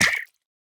Minecraft Version Minecraft Version latest Latest Release | Latest Snapshot latest / assets / minecraft / sounds / entity / pufferfish / hurt1.ogg Compare With Compare With Latest Release | Latest Snapshot
hurt1.ogg